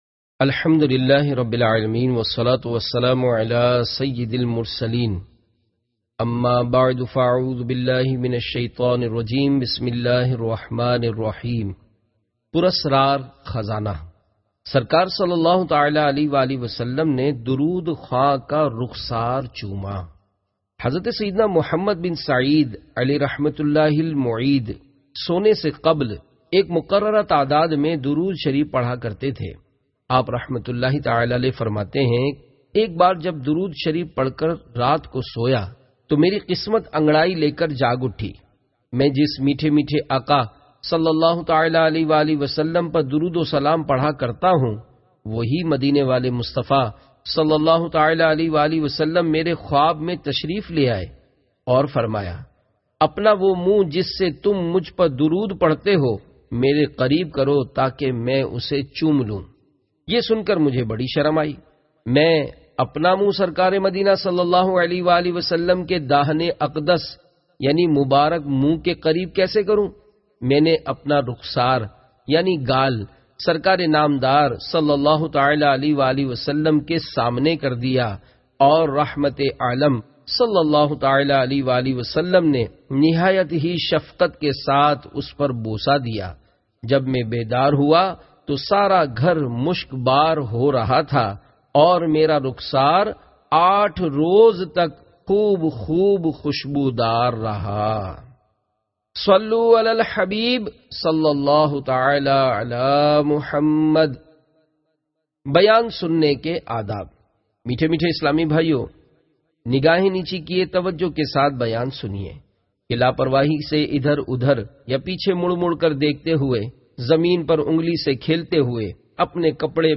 Audio Book - Pur Asrar Khazana